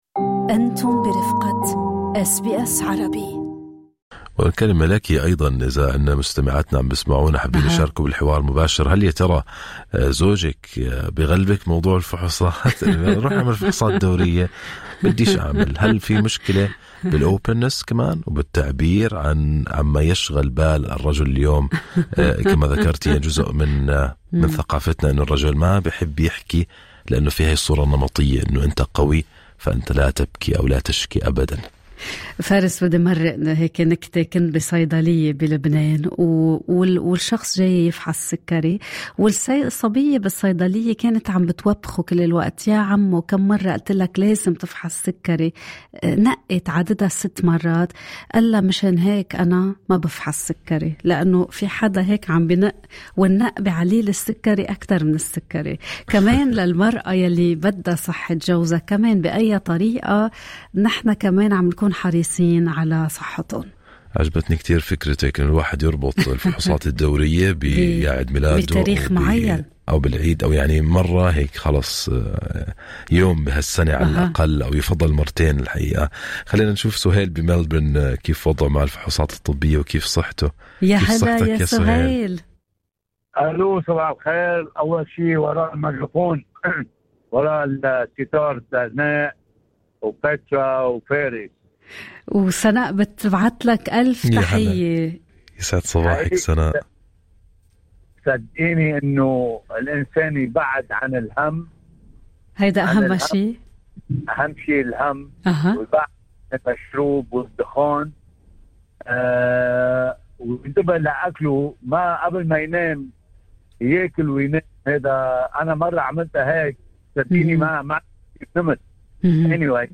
في فقرة الحوار المباشر ببرنامج صباح الخير أستراليا، شارك المستمعون قصصاً شخصية وصادقة عن رحلتهم مع الفحوصات الدورية، خسارة الوزن، تغيير نمط الحياة، وتحدّي الصورة النمطية التي تمنع الكثير من الرجال الحديث عن صحتهم.